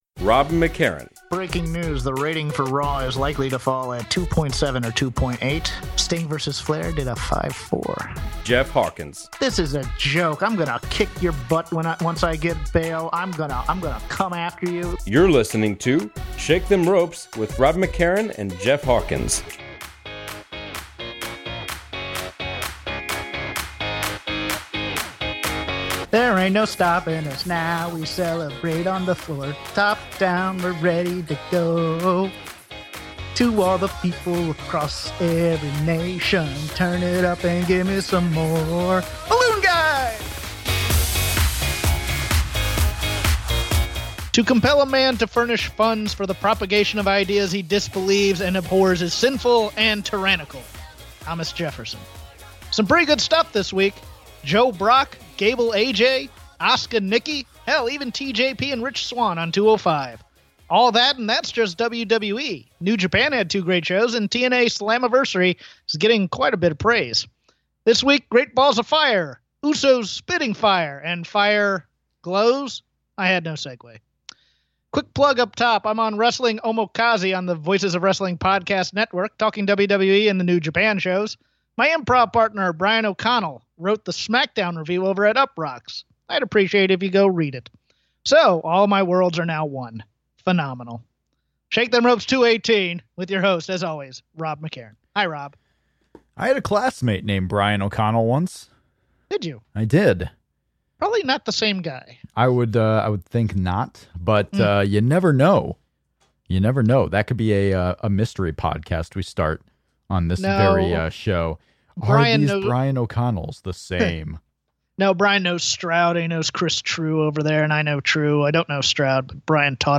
Plus, we're joined by a special guest to discuss the show!